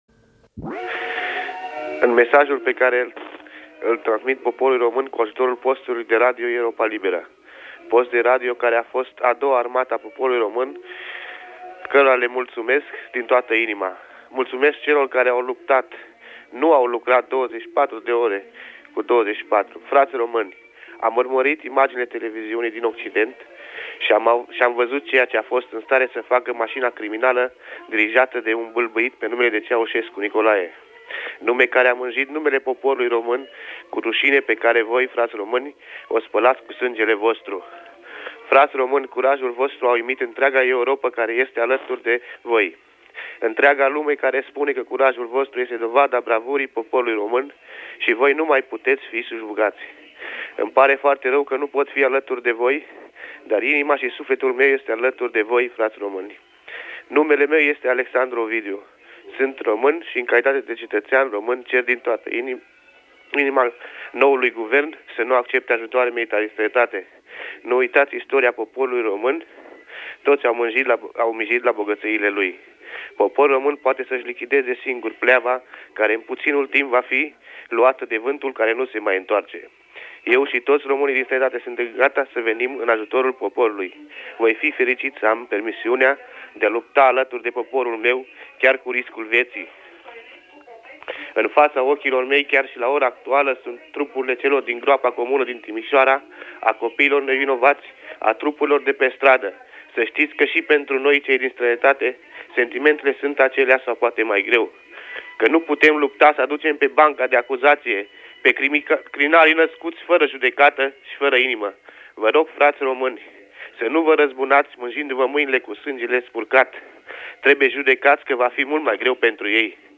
This broadcast, from Radio Bucharest is a combination of eyewitness accounts, messages to separated loved ones – commentary and reports from other news sources – I haven’t yet been able to sift through all the reports from all the days preserved, but needless to say it chronicles a tumultuous time, not only in Romania but Eastern Europe in General as the former Communist Bloc nations declare independence, one after the other.